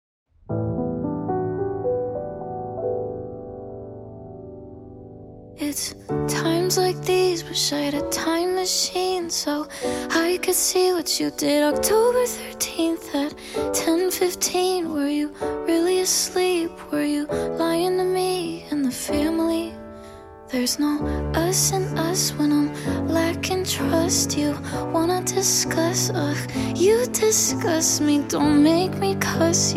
Pop / Teen Pop / Singer-Songwriter